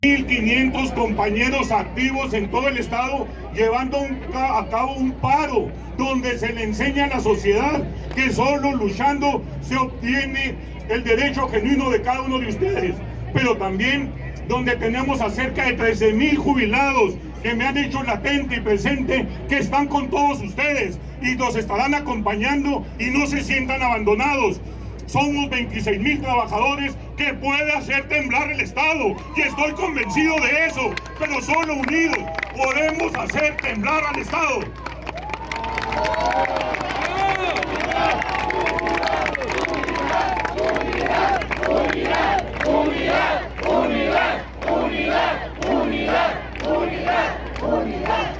Maestras y maestros adscritos a la Sección 42 del Sindicato Nacional de Trabajadores de la Educación (SNTE) se congregaron esta mañana en la Plaza del Ángel, frente a Palacio de Gobierno, en donde realizaron una manifestación pacífica para exigirle al gobierno que cumpla con sus peticiones para mejorar la condición laboral y hacer valer los derechos de los docentes.